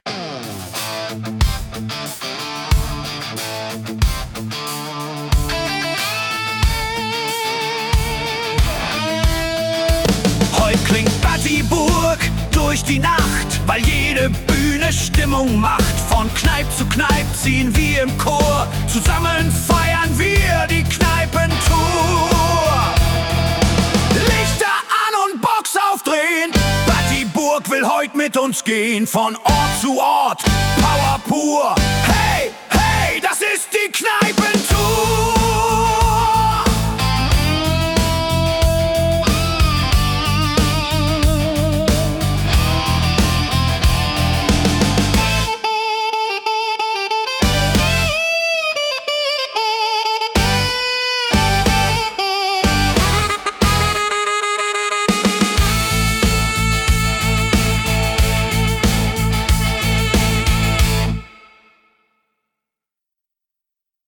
Etwas dynamischer...